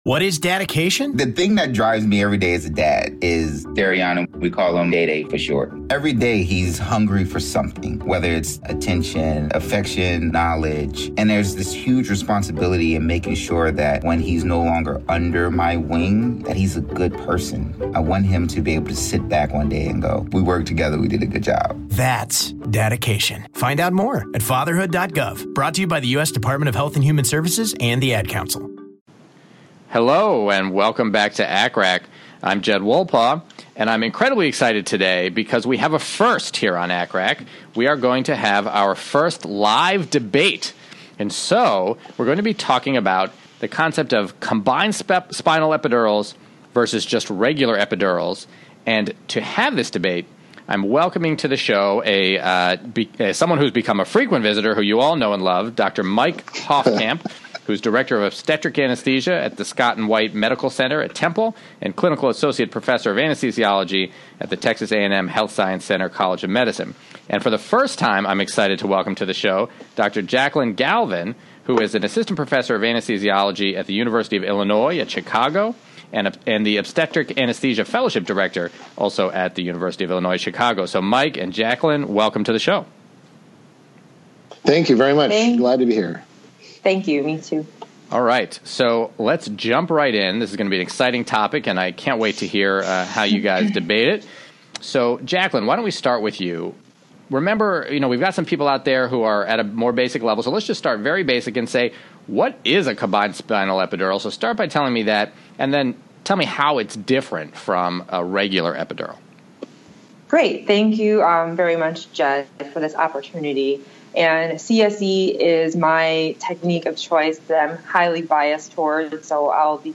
CSE vs Epidural Debate